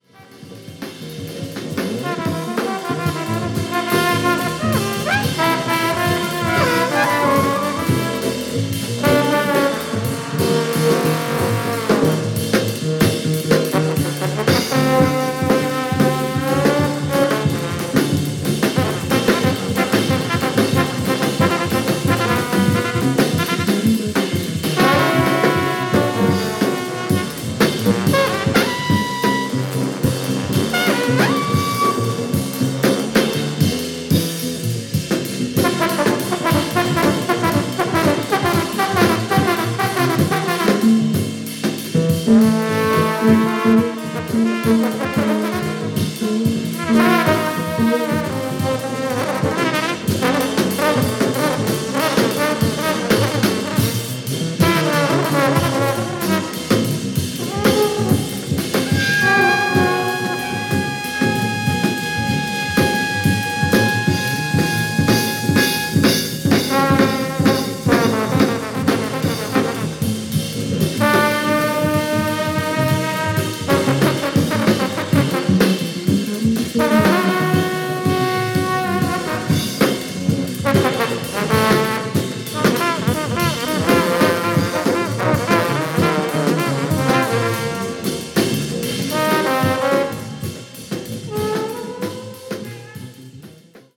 ワイルドなメンバーが揃うとこうなりますね！！！